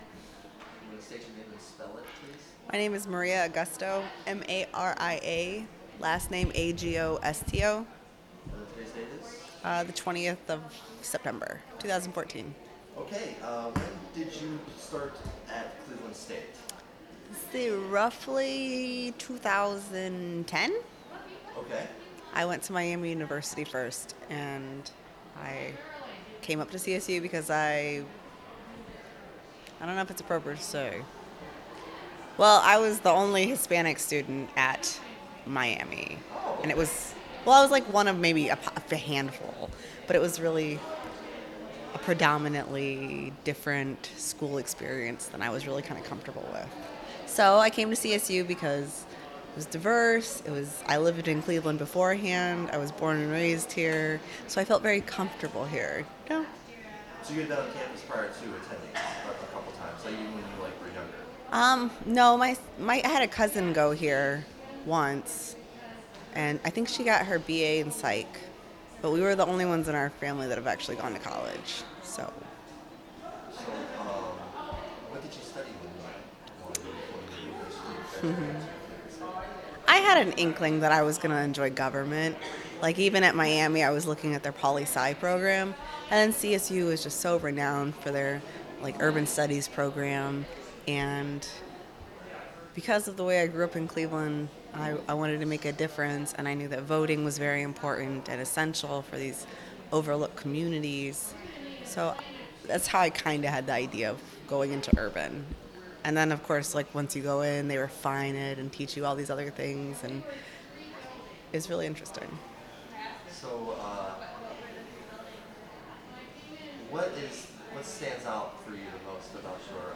Oral history interviews with select alumni, trustees, faculty, and other friends of Cleveland State University, conducted by staff at the CSU Center for Public History and Digital Humanities in coordination with the Office of the President and Office of Alumni Affairs on the occasion of CSU's 50th anniversary.
Interview